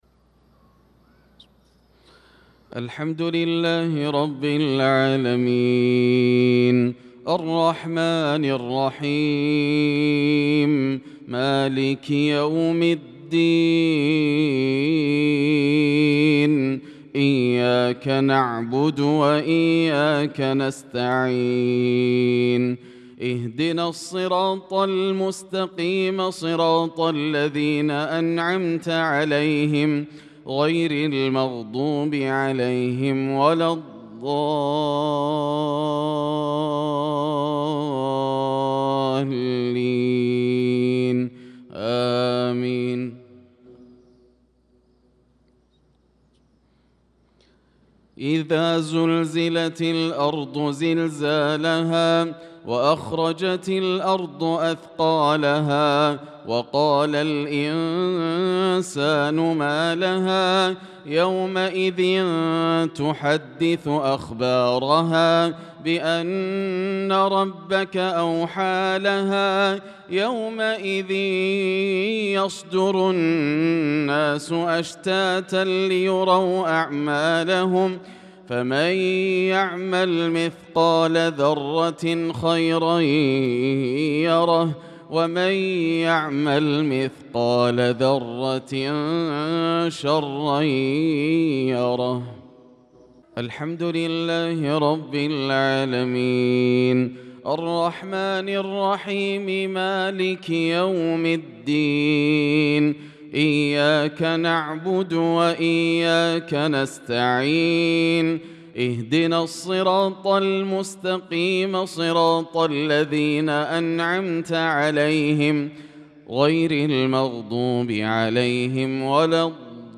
صلاة المغرب للقارئ ياسر الدوسري 11 شوال 1445 هـ
تِلَاوَات الْحَرَمَيْن .